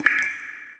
DRIPWAT05.mp3